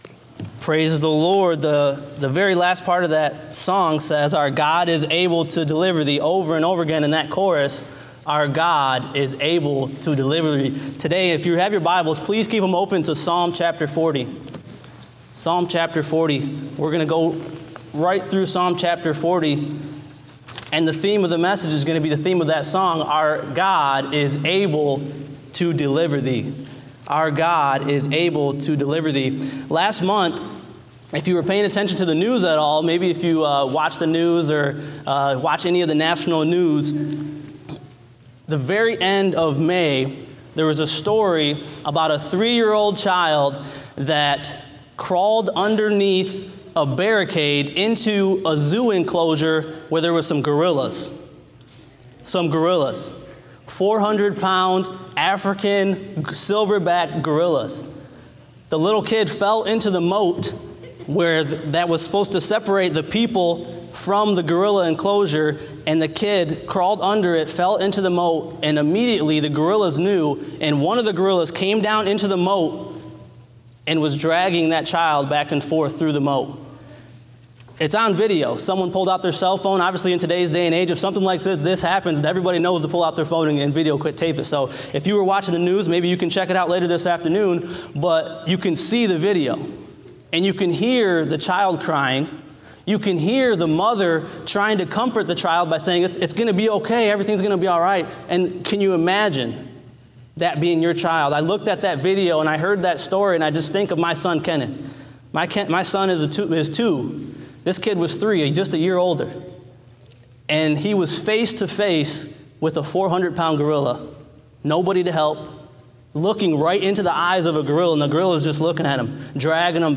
Date: July 3, 2016 (Morning Service)